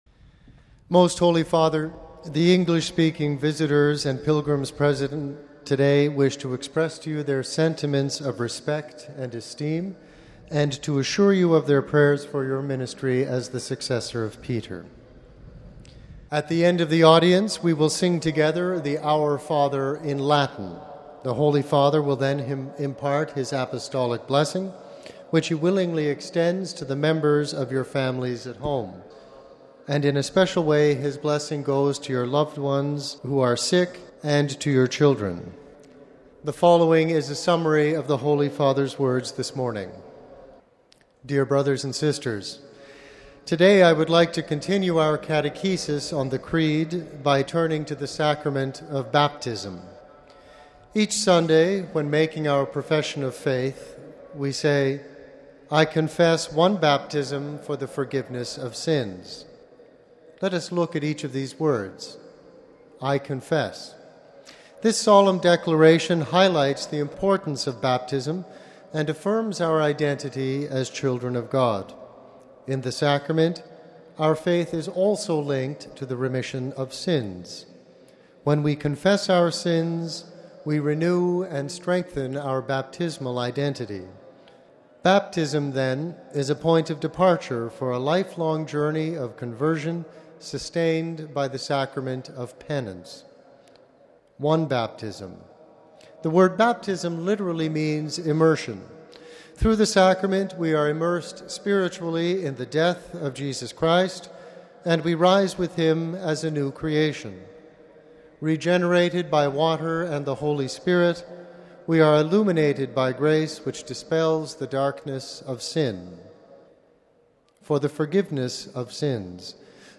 The general audience of Nov. 13 was held in the open, in Rome’s St. Peter’s Square.
Basing himself on the scripture passage, Pope Francis delivered his main discourse in Italian - summaries of which were read out by aides in various languages, including in English. But first, the aide greeted the Pope on behalf of the English-speaking pilgrims.